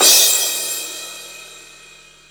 CRASH05   -R.wav